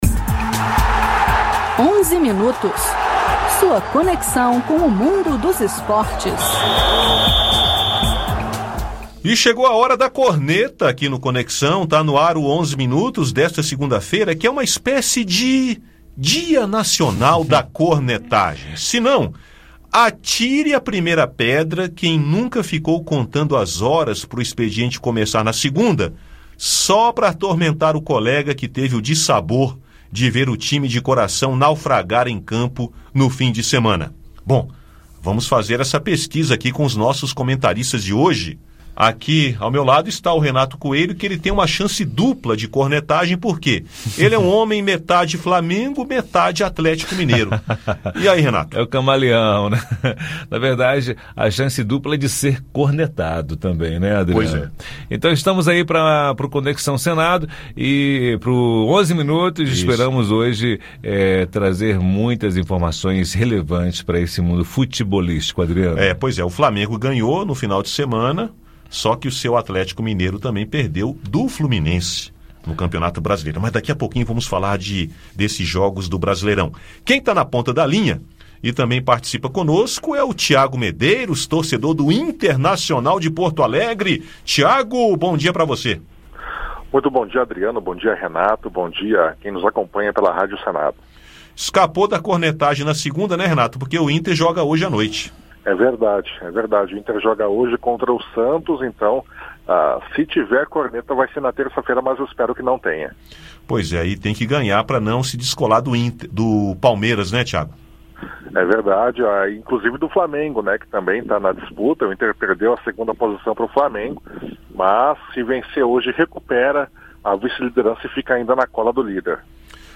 A violência dentro e fora dos estádios de futebol volta a ser tema no bate-papo do Onze Minutos.